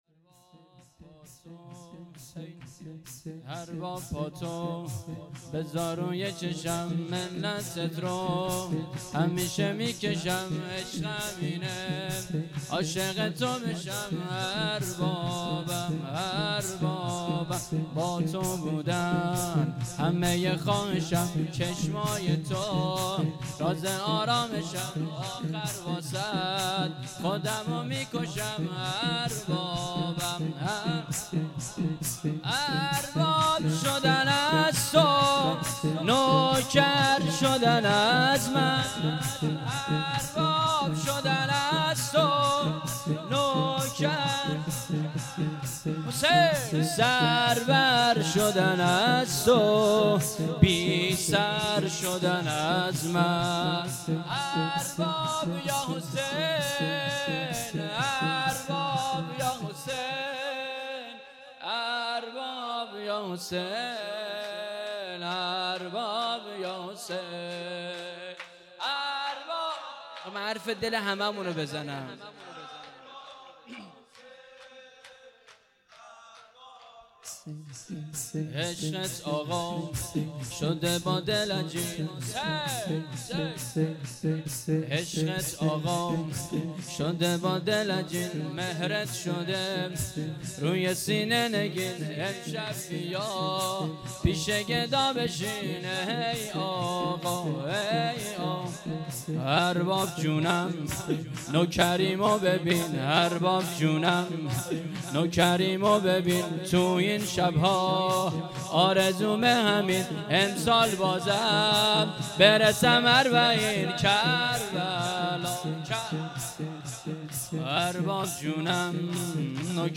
شور
شبی با شهدا ــ جلسه هفتگی ۴ مهر ۱۳۹۸